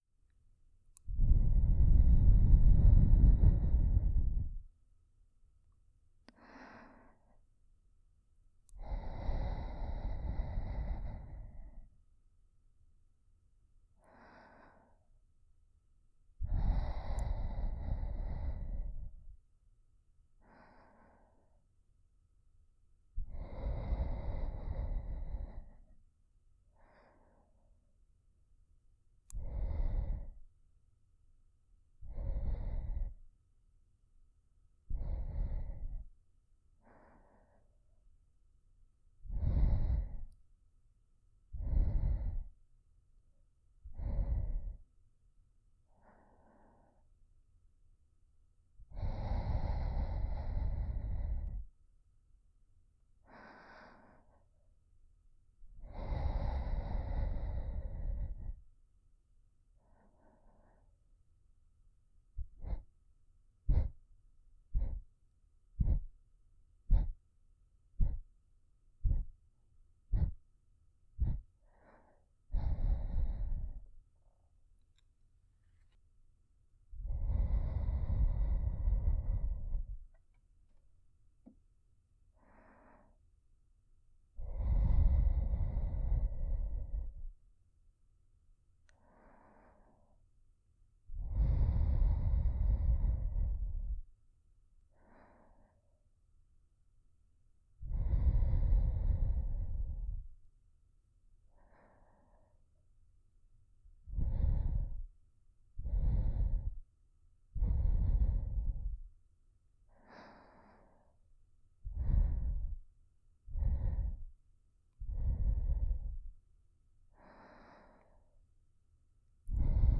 04.吐息パートのみ.wav